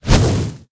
Minecraft Version Minecraft Version 25w18a Latest Release | Latest Snapshot 25w18a / assets / minecraft / sounds / mob / ghast / fireball4.ogg Compare With Compare With Latest Release | Latest Snapshot
fireball4.ogg